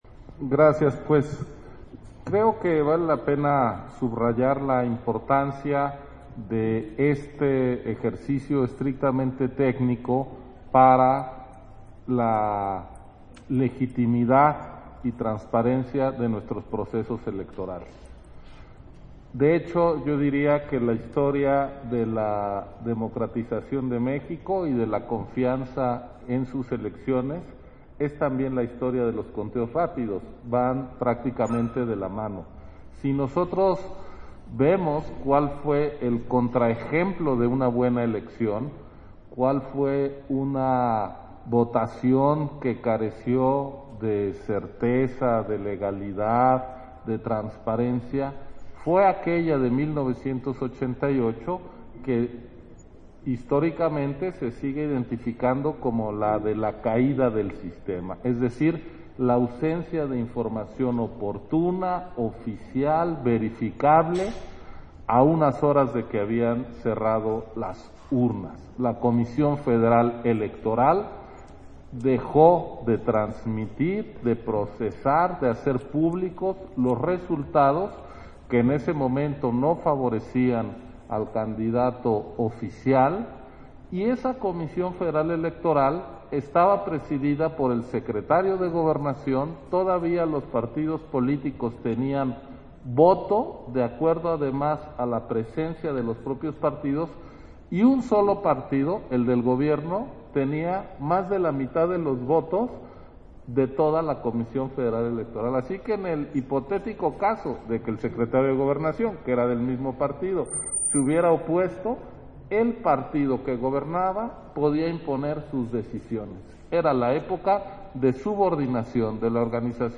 Intervención de Ciro Murayama, durante la instalación del Comité Técnico Asesor de los Conteos Rápidos para las elecciones 2022